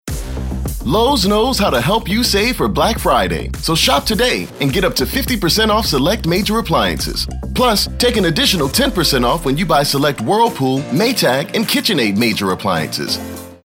announcer, confident, high-energy, promo, retail